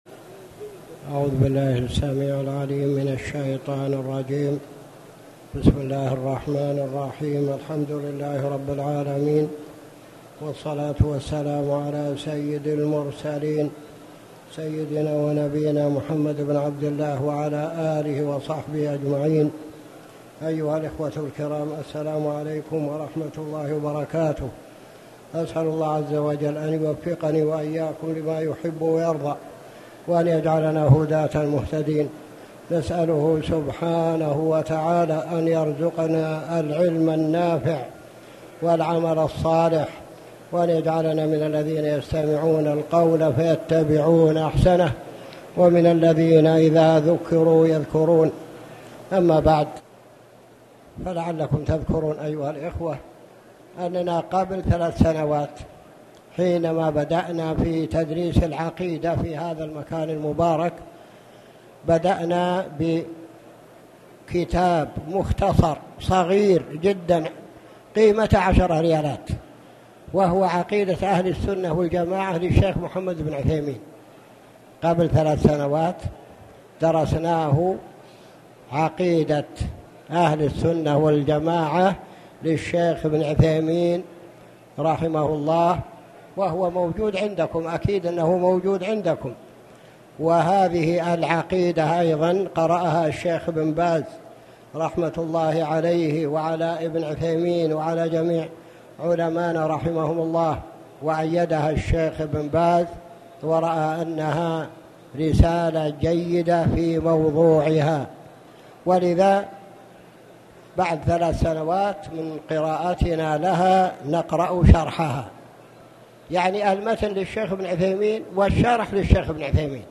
تاريخ النشر ٥ شعبان ١٤٣٨ هـ المكان: المسجد الحرام الشيخ